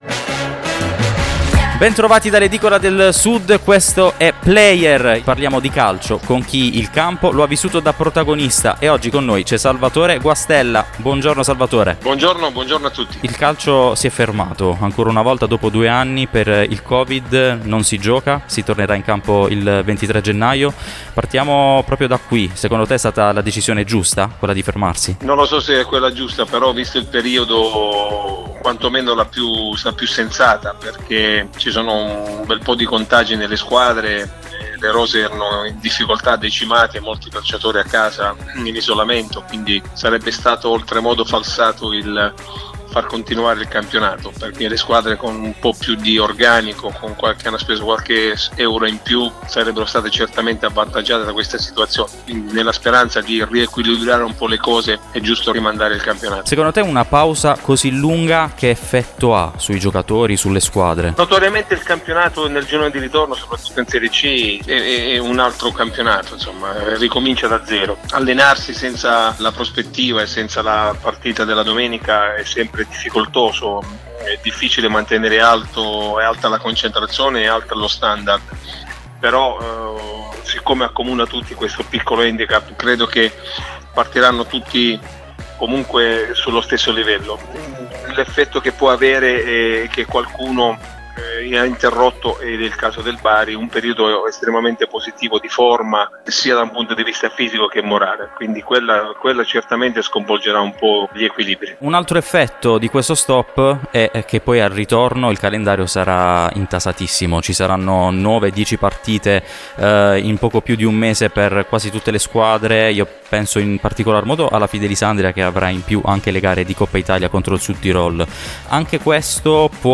Player, lo sport in radio.